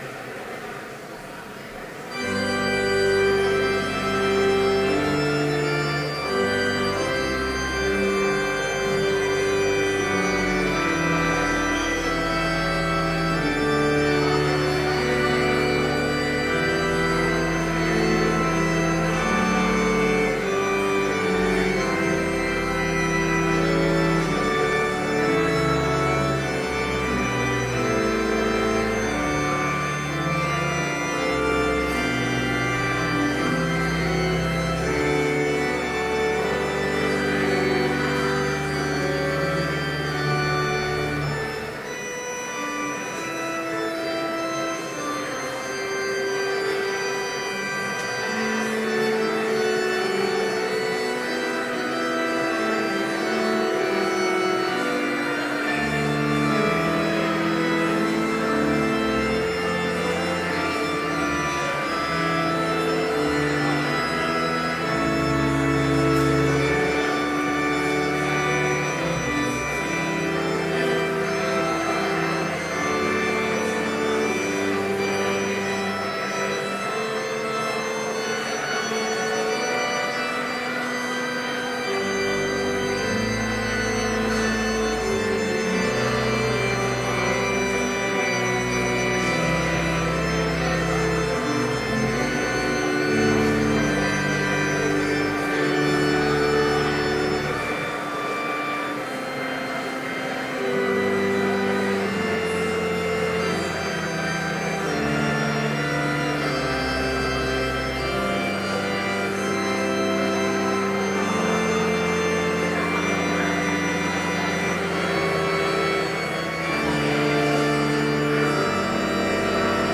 Complete service audio for Chapel - August 31, 2012